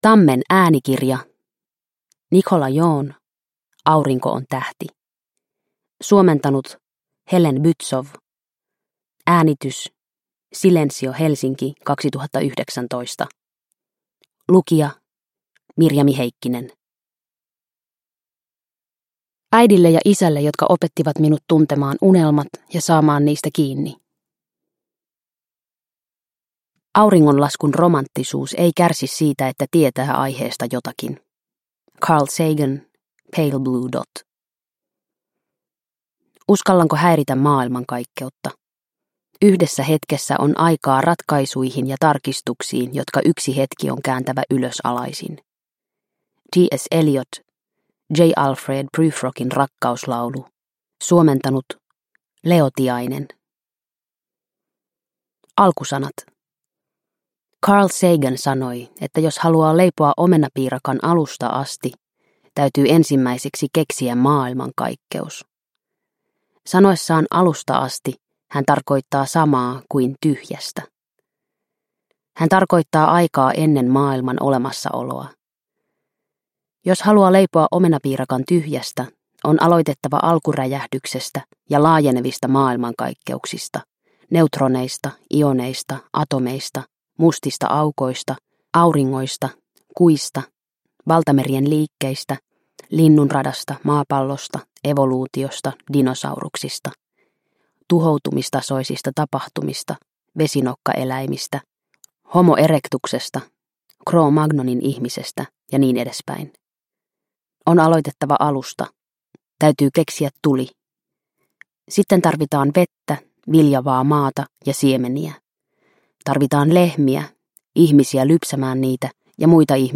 Aurinko on tähti – Ljudbok – Laddas ner